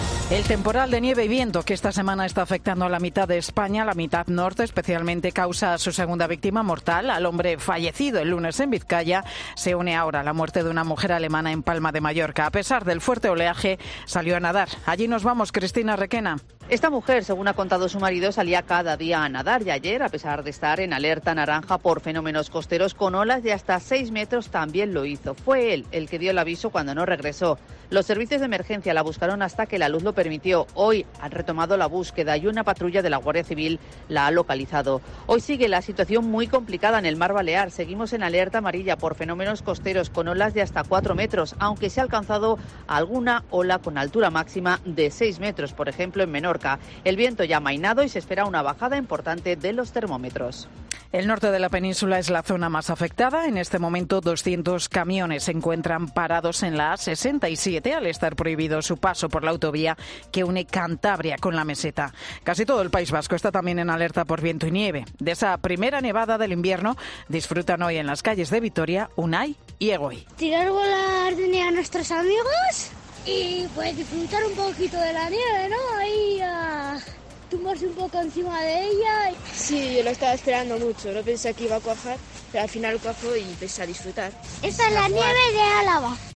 Los redactores de las emisoras COPE se desplazan a los puntos más complicados de nuestra geografía para informar de la última hora de la Borrasca 'Fien'